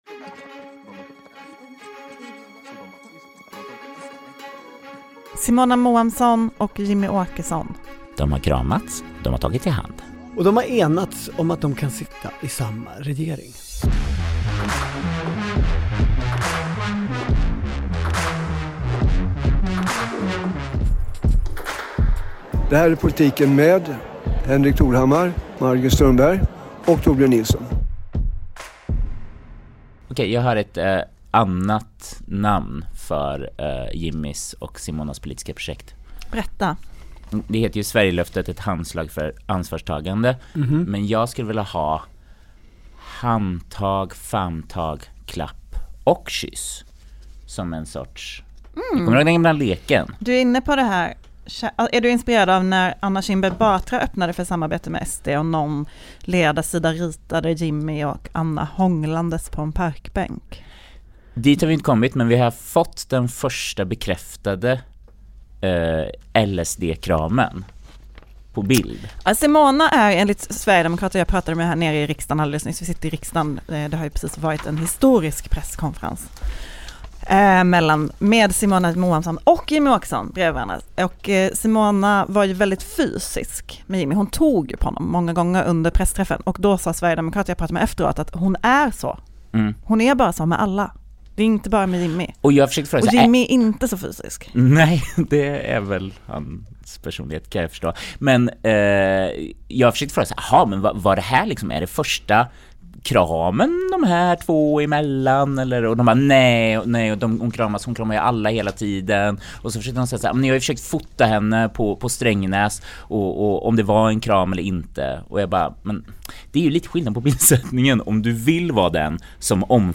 Podden spelas in i riksdagen precis efter den historiska pressträffen där Simona Mohamsson och Jimmie Åkesson berättar att de ska kunna sitta i samma regering efter nästa val.